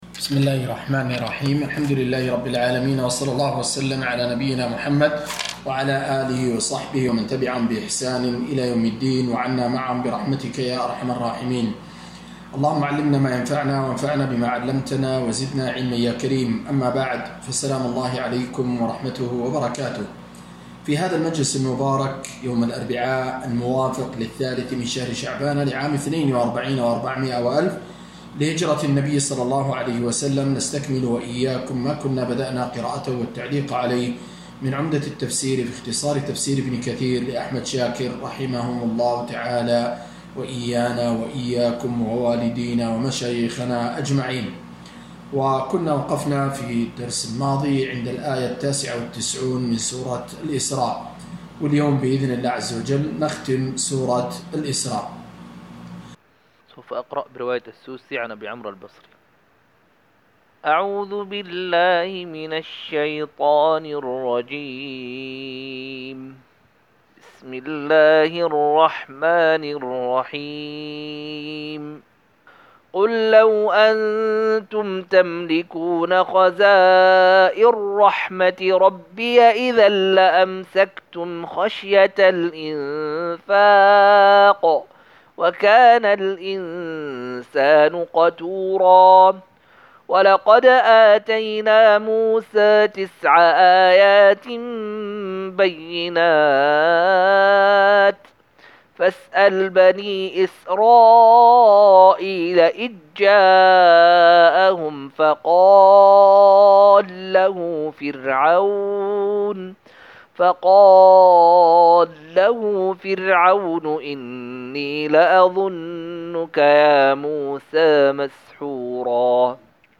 268- عمدة التفسير عن الحافظ ابن كثير رحمه الله للعلامة أحمد شاكر رحمه الله – قراءة وتعليق –